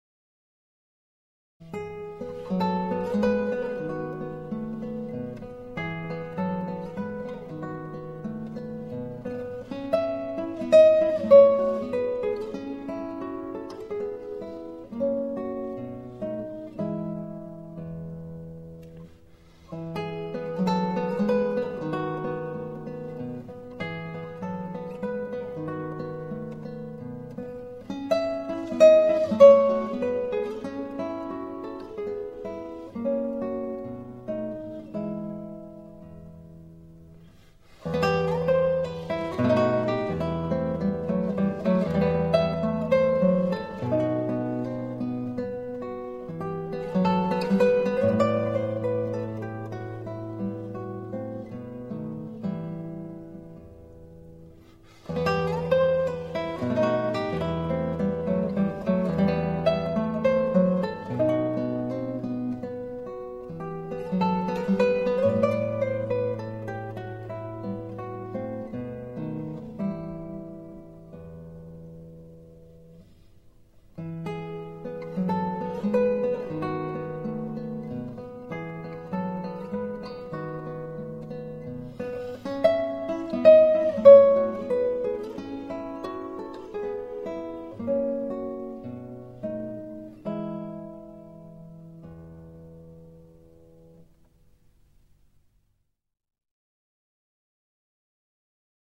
0195-吉他名曲拉格里姆的四首马祖卡.mp3